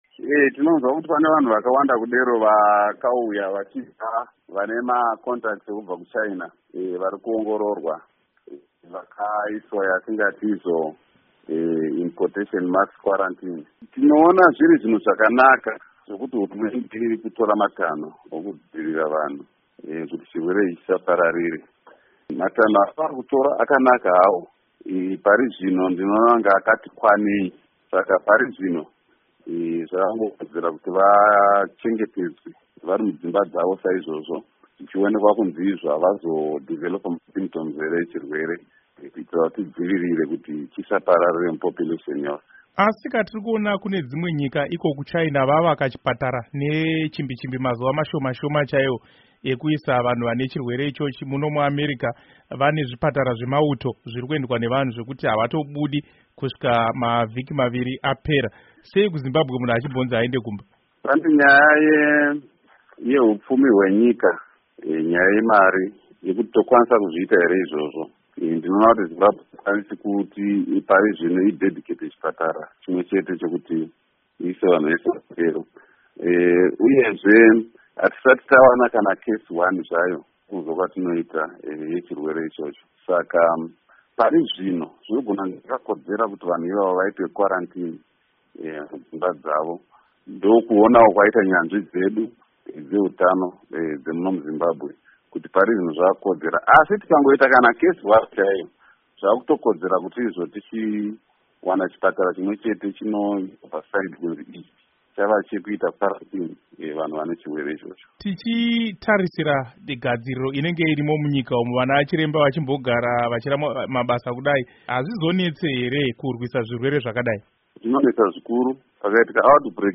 Hurukuro naDoctor Henry Madzorera